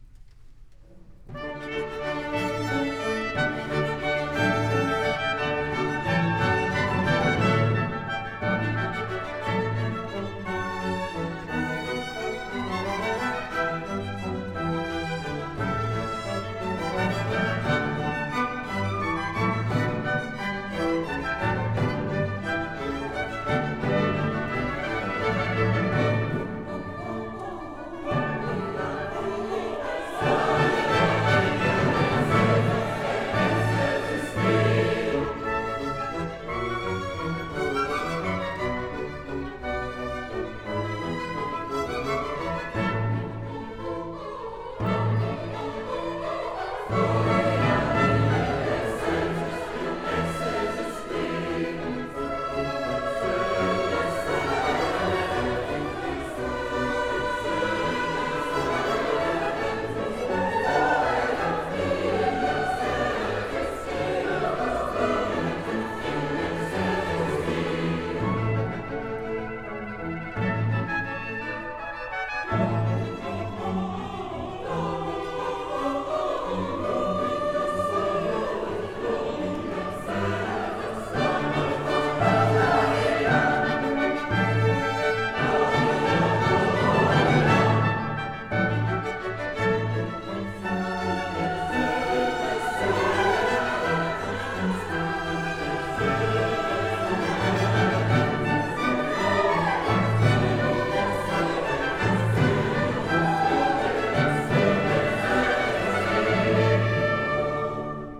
Emmauskirche
Chöre
Kammerchor
Orchester